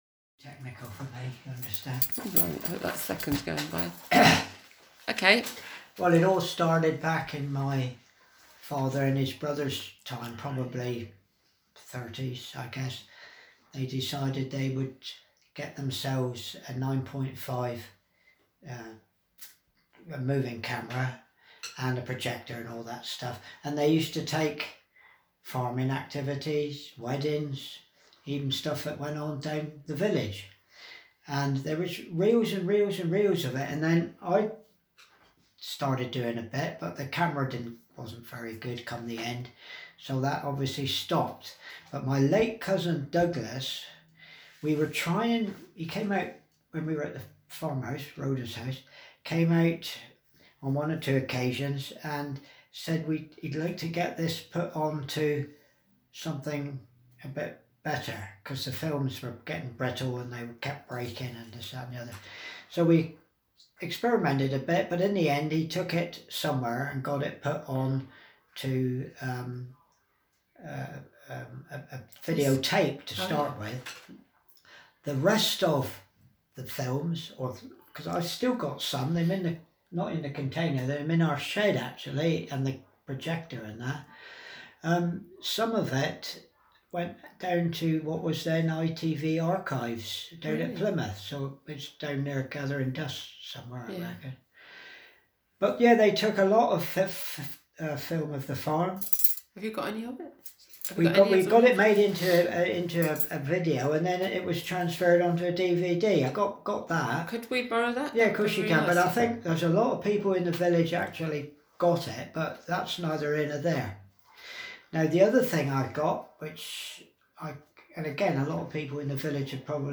Interview and transcription